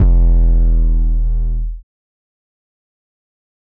skyfall 808.wav